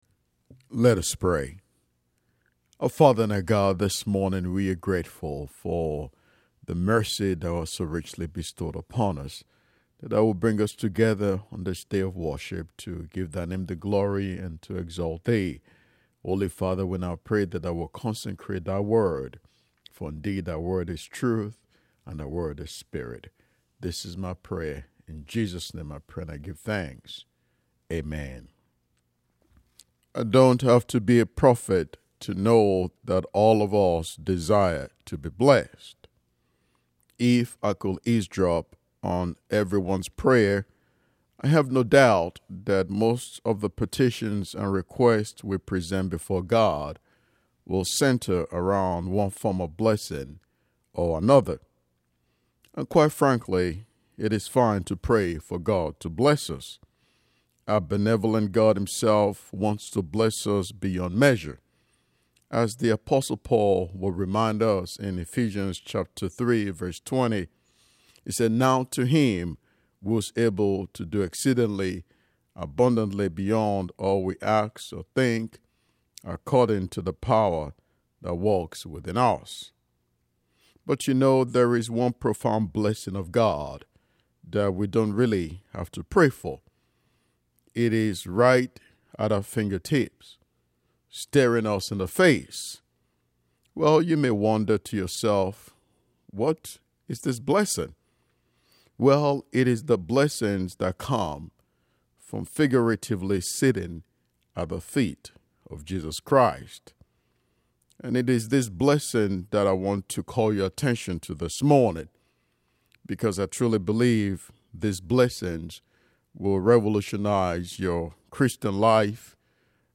Sat and Sun Sep 13th and 14th 2025 Click to listen to the sermon. https